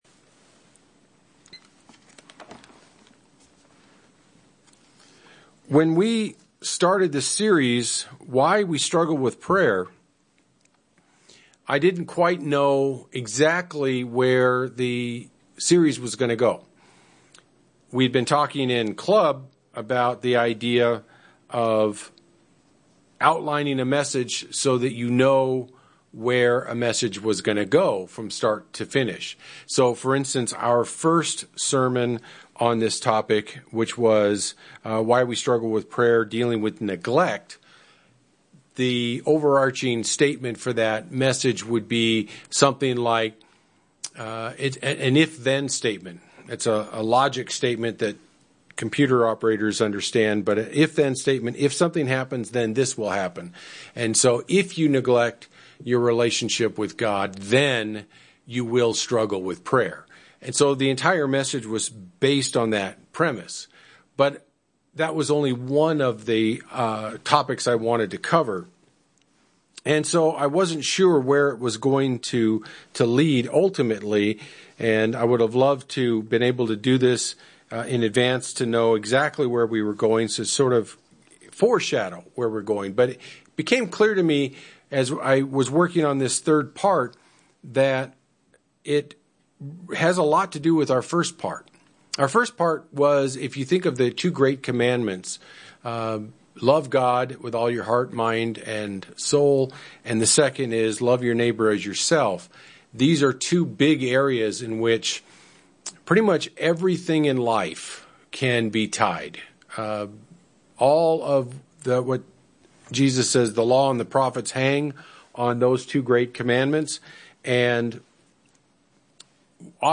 In the first two sermons, we focused on neglect and trials as reasons that we can struggle with prayer.